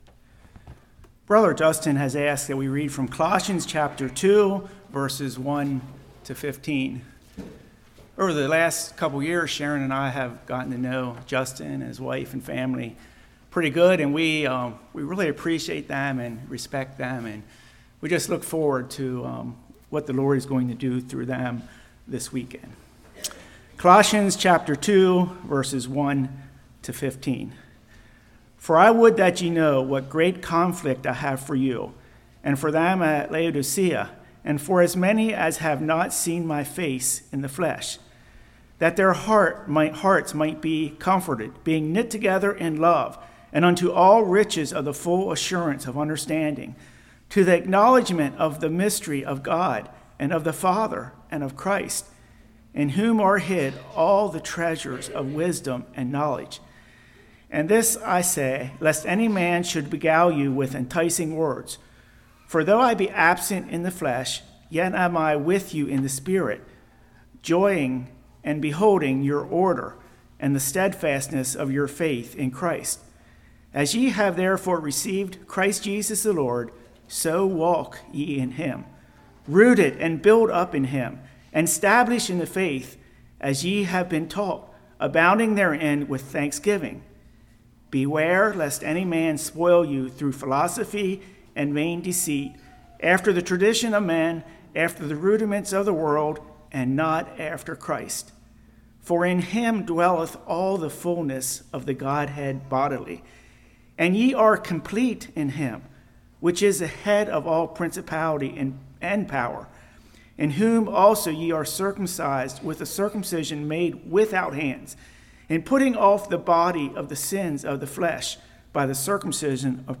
Col. 2:1-15 Service Type: Love Feast Our Identity in Christ Walking with God Having a firm foundation Being complete in Him Identity in Christ or Identity in what we have Removing sin.